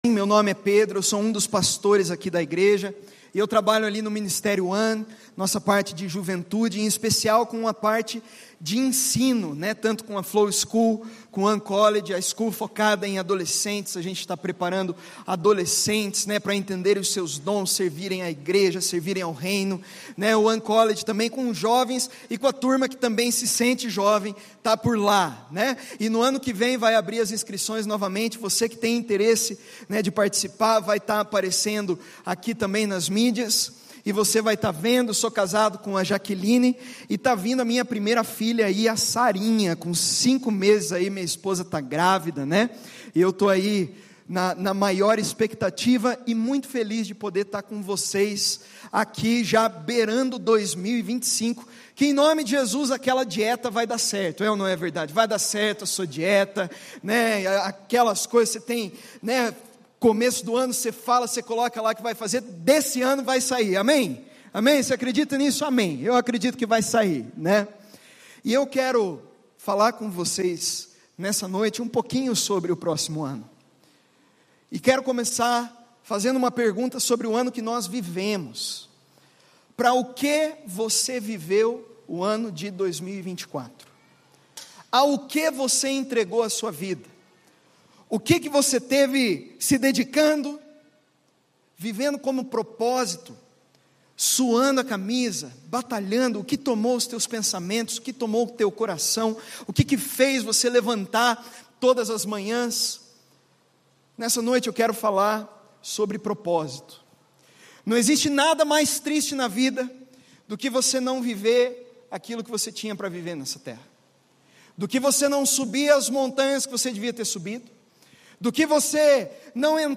Mensagem
na Primeira Igreja Batista de Curitiba.